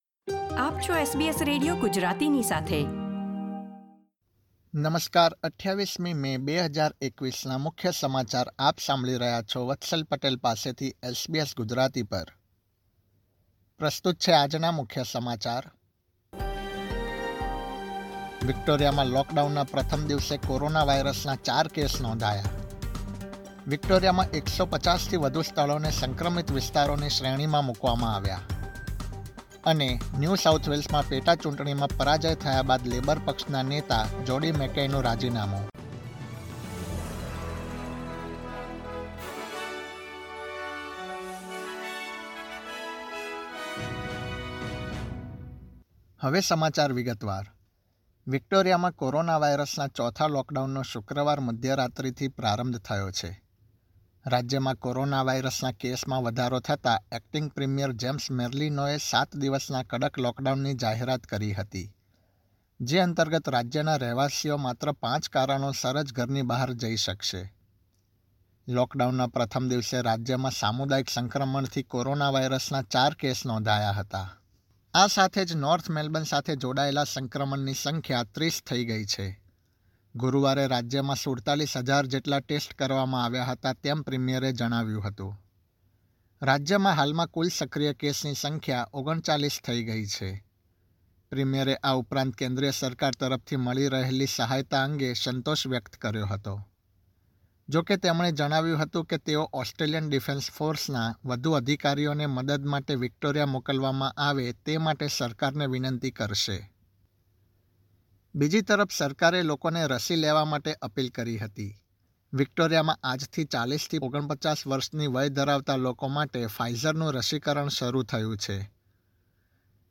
SBS Gujarati News Bulletin 28 May 2021
gujarati_2805_newsbulletin.mp3